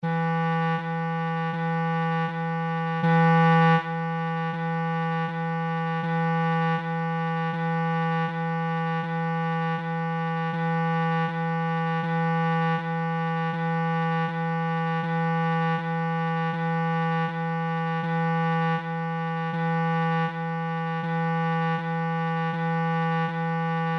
NOTAS MUSICAIS
NOTA MI